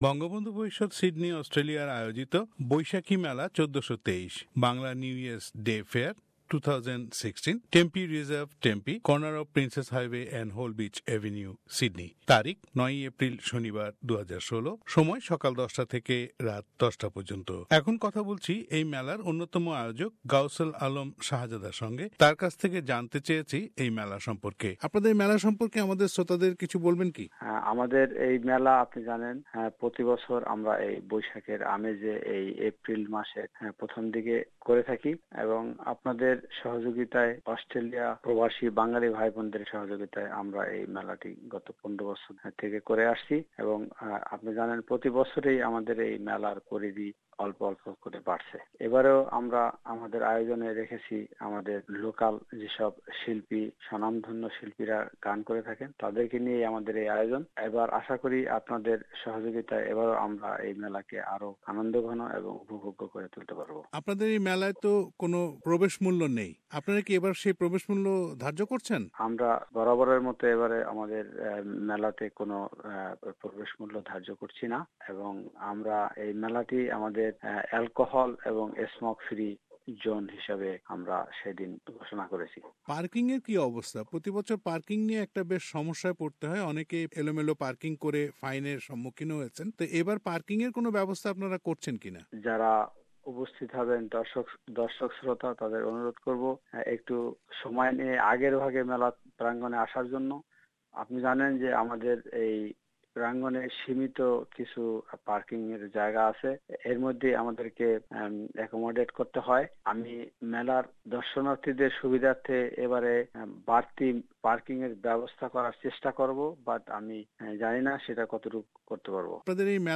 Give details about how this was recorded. Bangla New Year's Day Fair 2016 at Tempe,Sydney: Interview Bangla New Year's Day Fair 2016, on 9th April 2016 at Tempe Reserve,Tempe,NSW